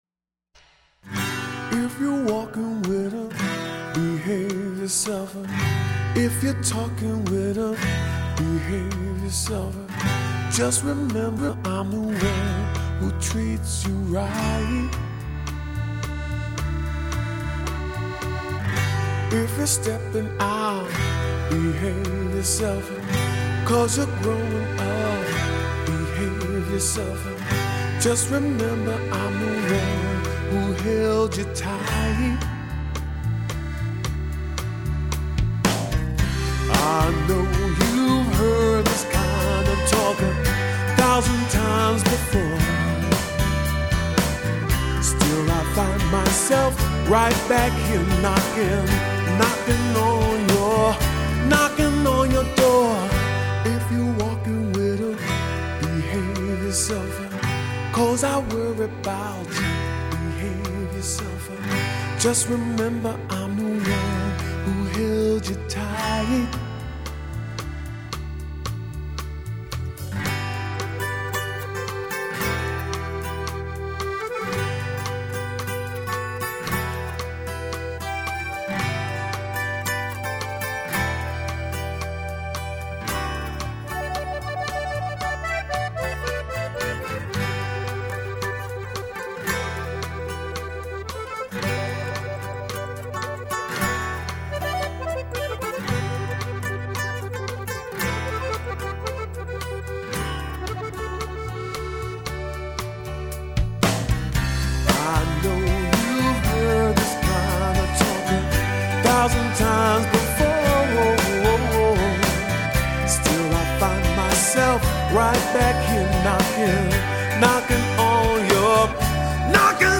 New York City Blues singer